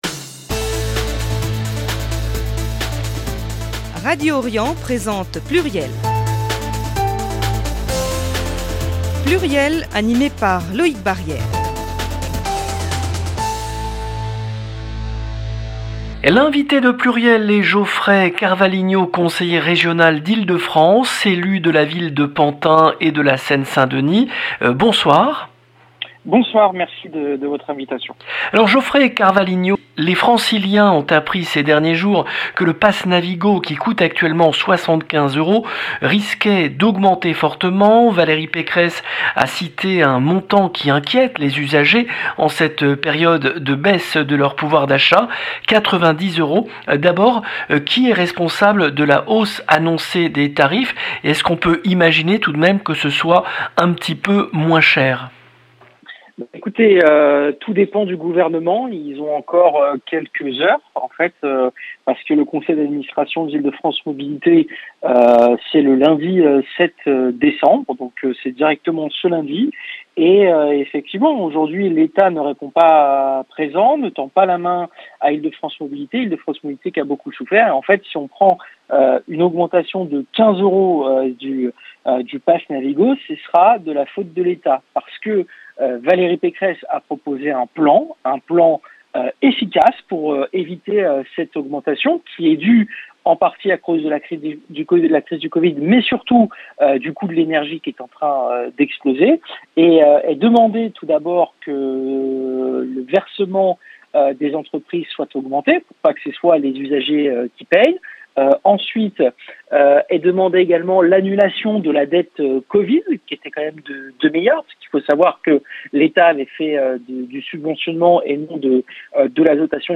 L’invité de PLURIEL est Geoffrey Carvalhinho, Conseiller Régional d’Île-de-France, Élu de la ville de Pantin et de la Seine-Saint-Denis